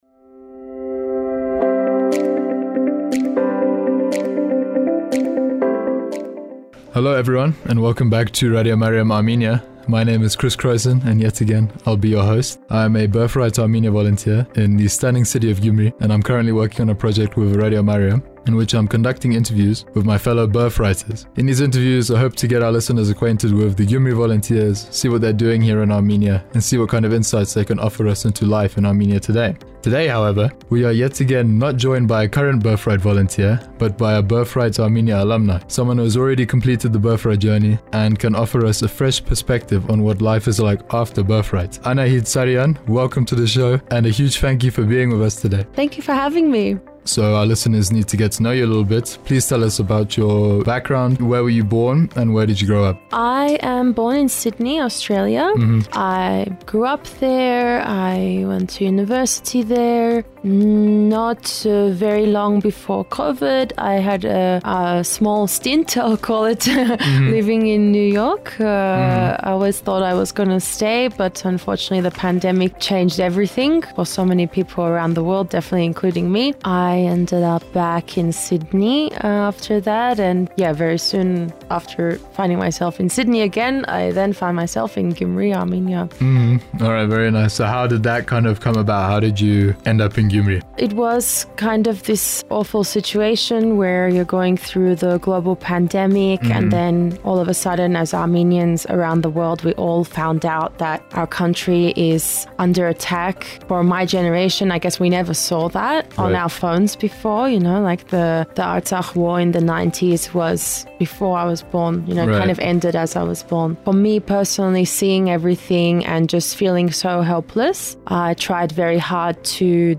Interviews with Gyumri’s Birthright Armenia alumni: Episode 2: